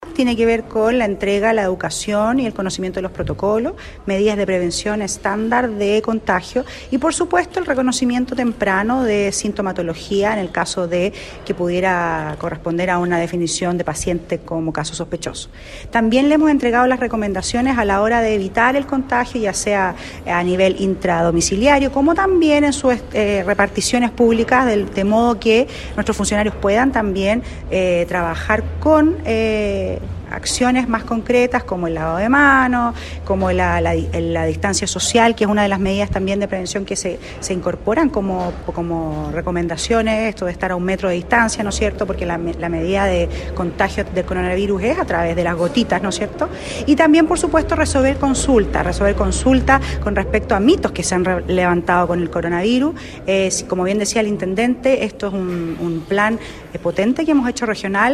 En dependencias de la gobernación, se reunieron jefes de servicios públicos para reforzar lineamientos del Plan de Acción de Covid 19 y las respectivas medidas de prevención adoptadas por el Gobierno, como lo expresó la seremi del ramo, Scarleth Molt.